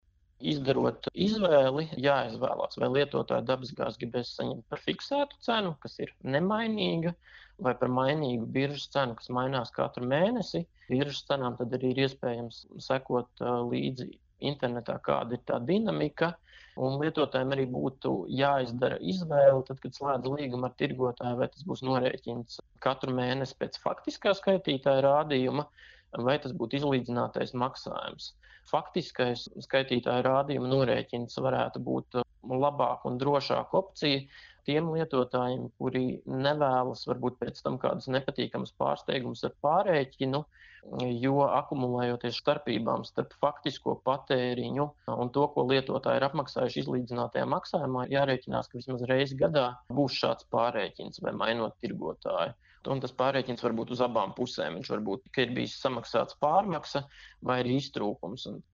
RADIO SKONTO Ziņās par to, kam pievērst uzmanību, pārslēdzot līgumu par dabasgāzes piegādi mājsaimniecībā